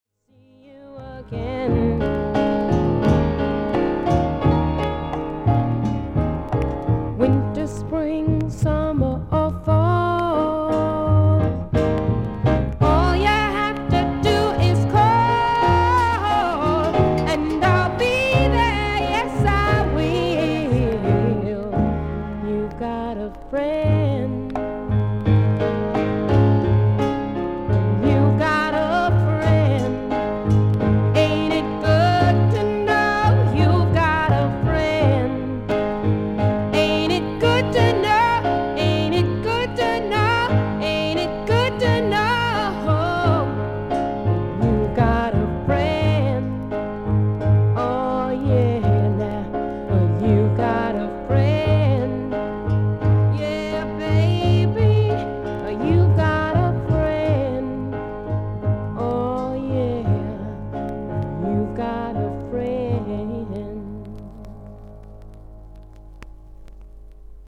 B1後半に5mmほどのキズ、軽い周回ノイズあり。
ほかはVG++〜VG+:少々軽いパチノイズの箇所あり。少々サーフィス・ノイズあり。クリアな音です。
女性シンガー/ソングライター。